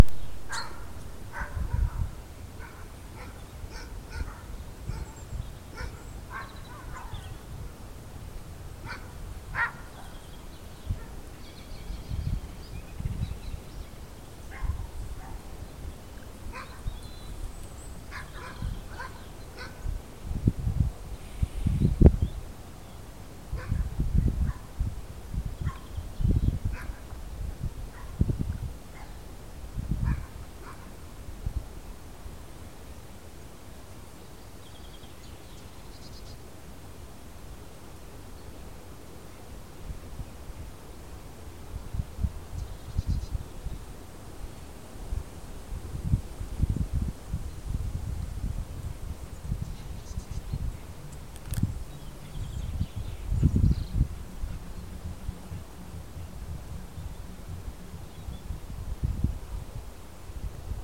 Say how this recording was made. sunday afternoon walk down the fields